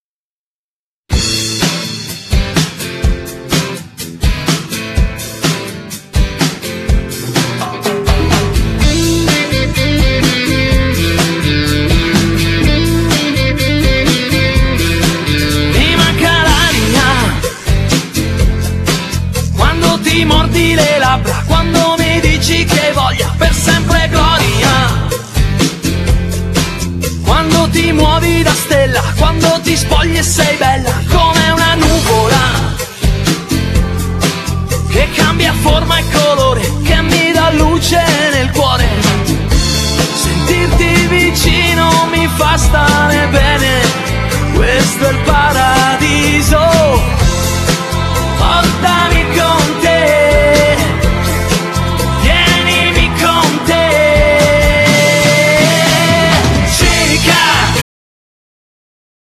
Genere : Pop
brano estivo ben eseguito e ritmato al punto giusto